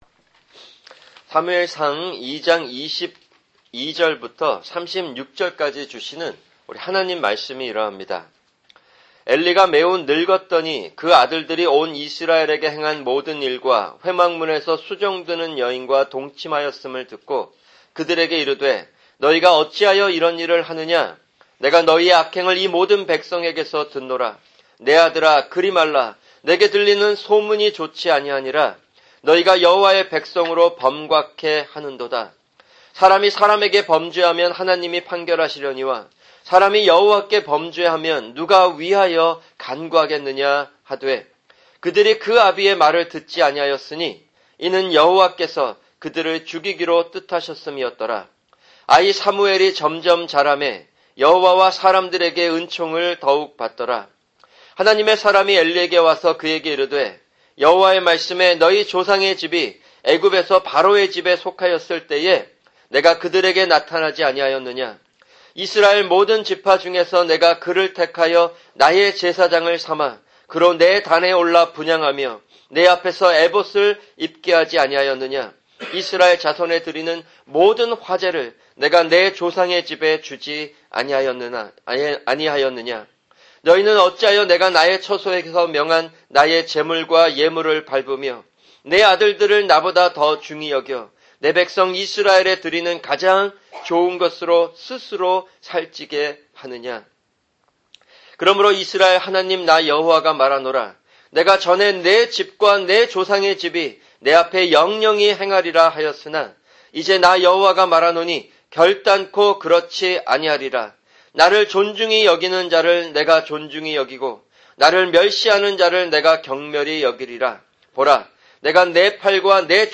[주일 설교] 사무엘상(8) 2:22-36(1)